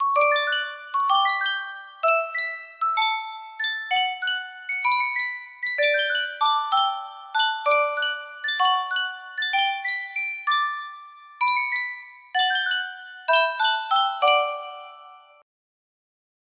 Type Full range
BPM 96